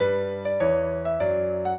piano
minuet2-10.wav